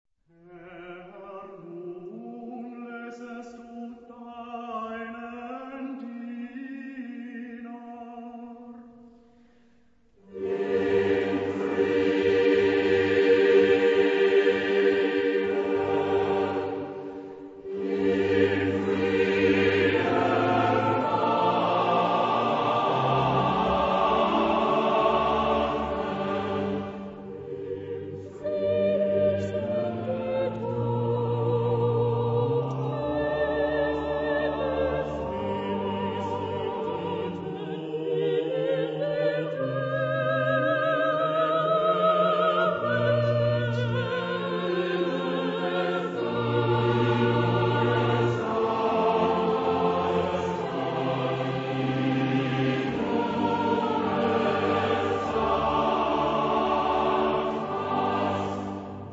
Genre-Style-Form: Sacred ; Baroque
Mood of the piece: polyphonic ; calm
Type of Choir: SATTB + SSB + SSB + SSB  (14 mixed voices )
Instruments: Basso continuo ; Instruments (8)